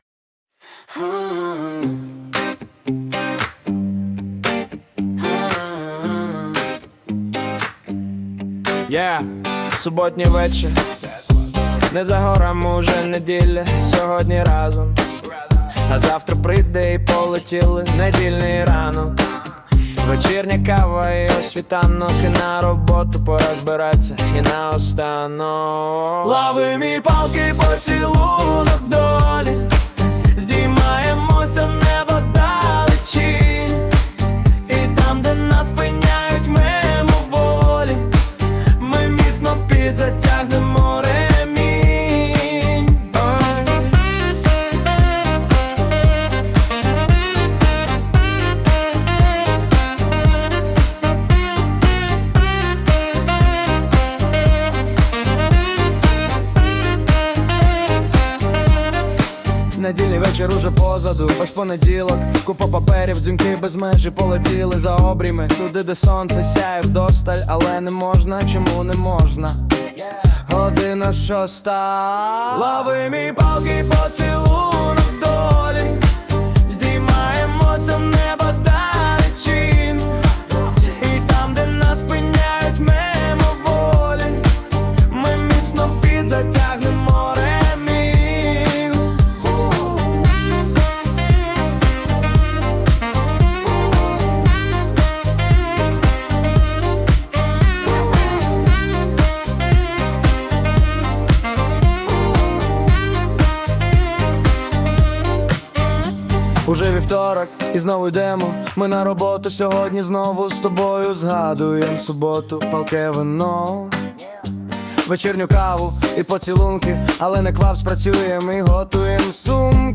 Стиль: Поп, реп